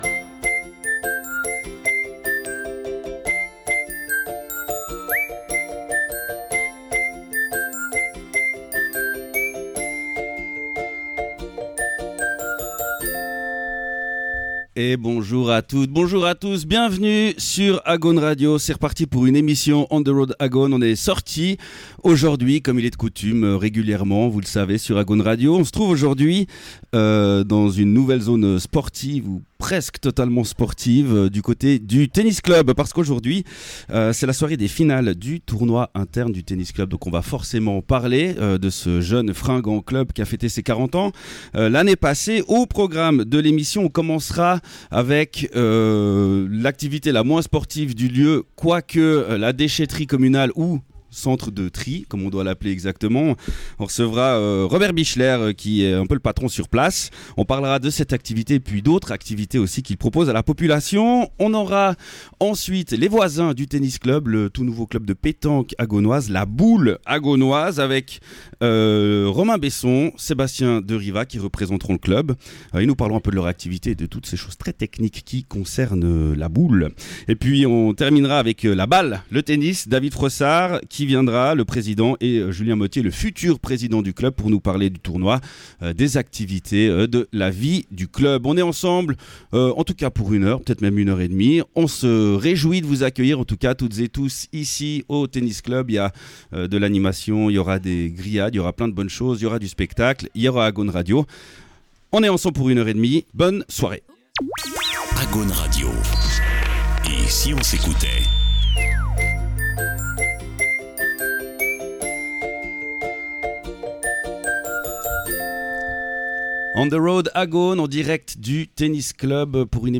On the Road Agaune du 29 août 2025 au Tennis Club de Saint-Maurice, où l'on parle :